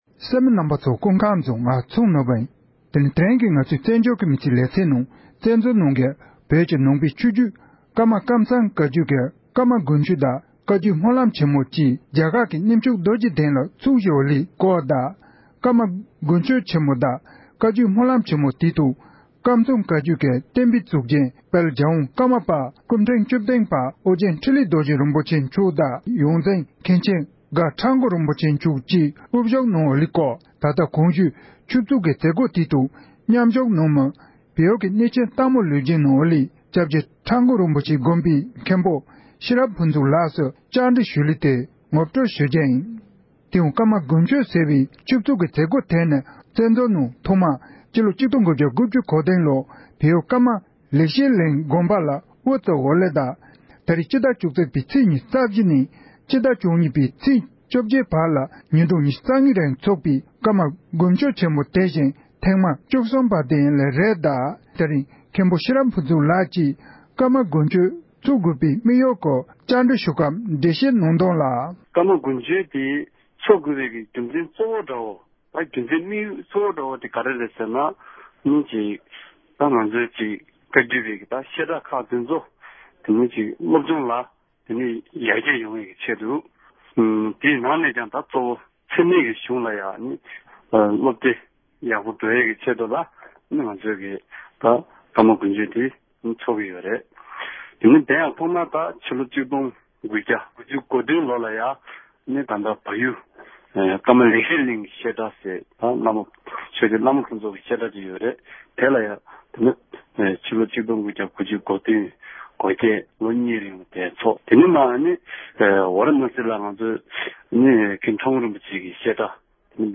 བཀའ་འདྲི